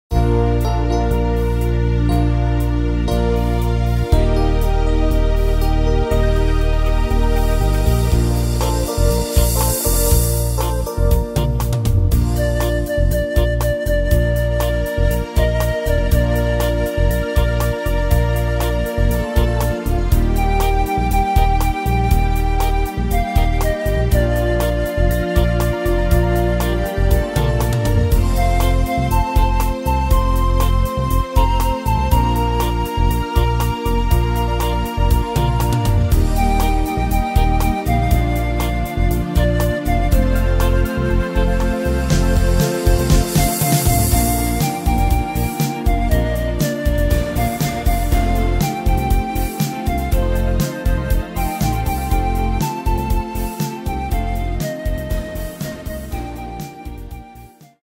Tempo: 120 / Tonart: Bb-Dur